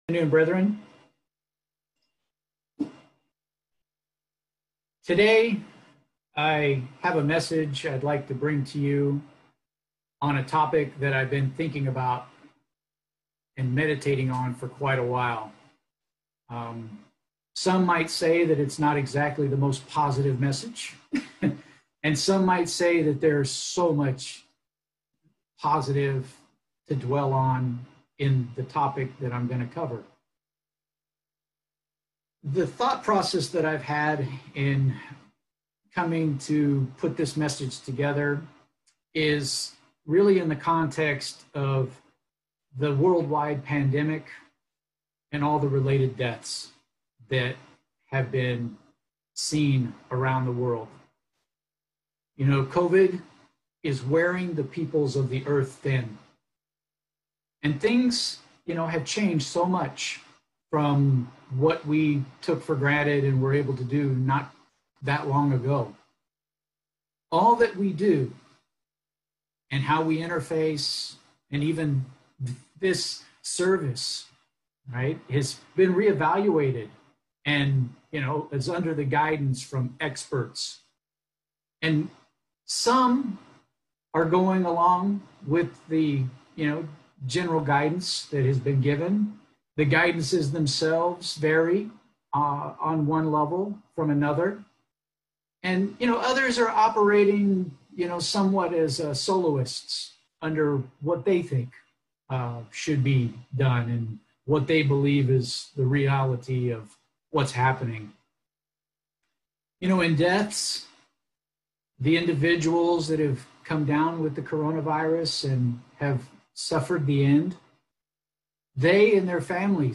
Given in Petaluma, CA